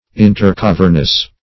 intercavernous - definition of intercavernous - synonyms, pronunciation, spelling from Free Dictionary
Search Result for " intercavernous" : The Collaborative International Dictionary of English v.0.48: Intercavernous \In`ter*cav"ern*ous\, a. (Anat.)
intercavernous.mp3